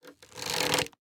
Minecraft Version Minecraft Version 25w18a Latest Release | Latest Snapshot 25w18a / assets / minecraft / sounds / item / crossbow / loading_middle2.ogg Compare With Compare With Latest Release | Latest Snapshot